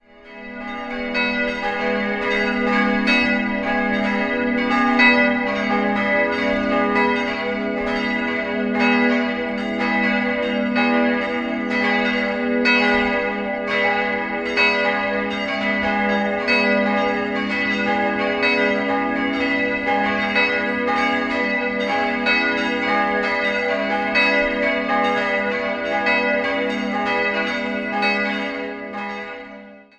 Die beiden größeren Glocken wurden 1954 von Friedrich Wilhelm Schilling gegossen. Von den beiden kleinen wurde die eine im Jahr 1518 gegossen, die andere entstand 1684 bei Wolf Hieronymus Heroldt in Nürnberg.